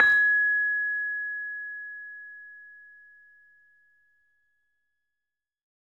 LAMEL G#5 -L.wav